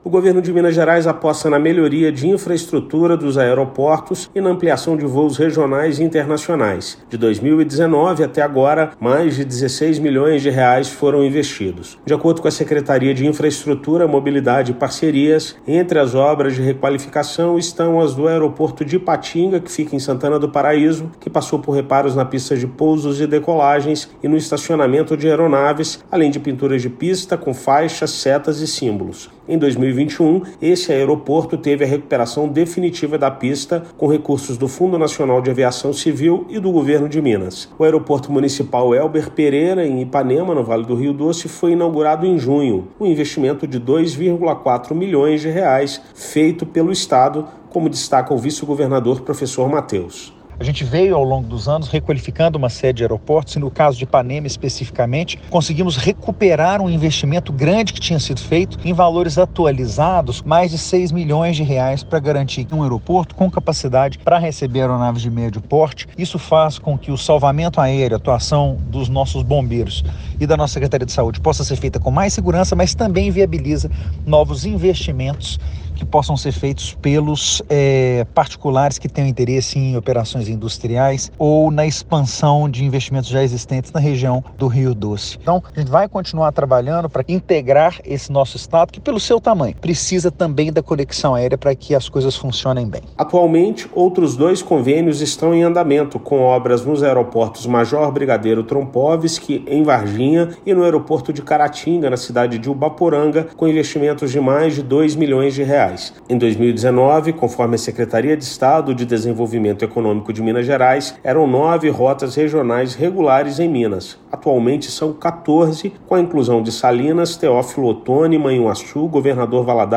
Além da crescente demanda de passageiros, intervenções facilitam acesso de investidores no estado, bem com os pousos e decolagens de aeronaves das áreas de saúde e segurança pública. Ouça matéria de rádio.